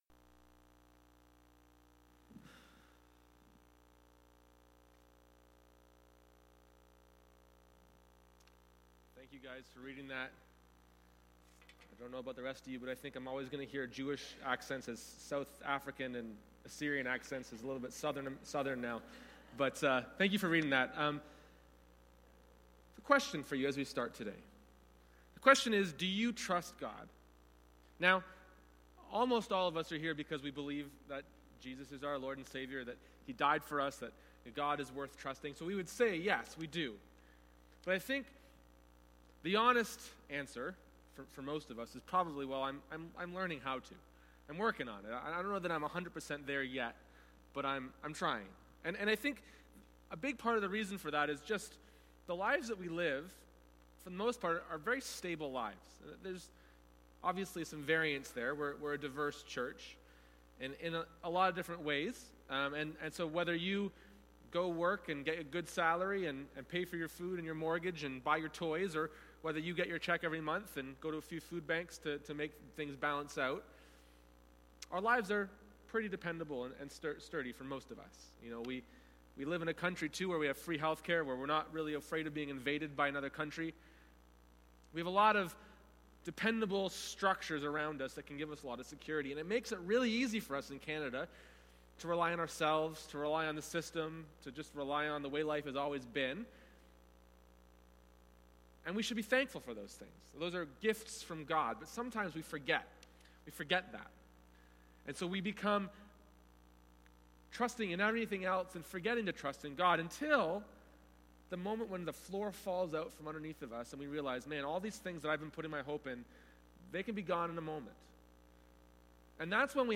Sermons - Forward Baptist Church, Toronto